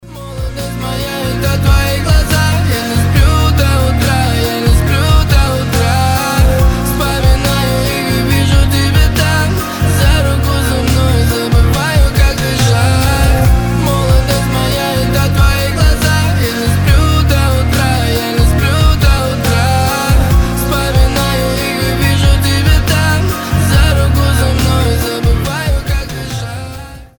мужской голос
лирика